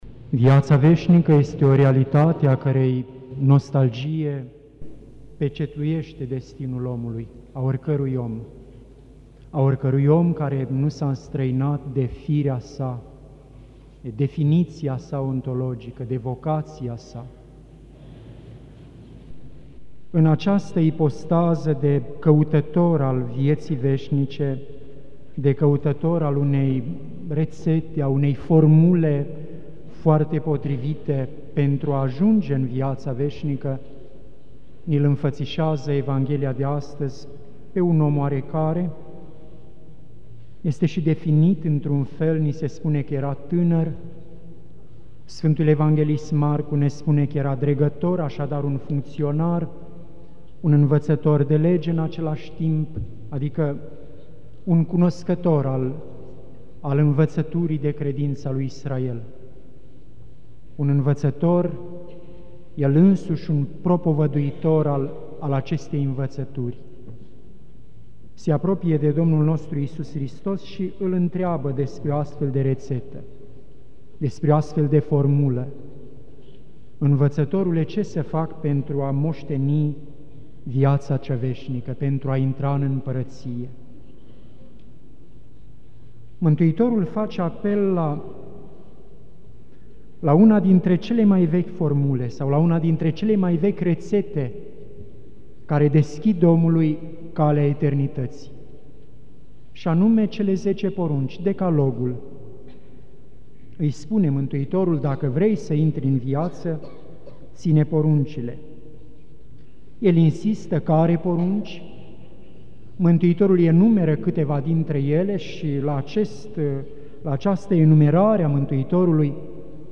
Predică la sărbătoarea Sfântului Apostol Andrei, cel întâi chemat, Ocrotitorul României
Cuvinte de învățătură Predică la sărbătoarea Sfântului Apostol Andrei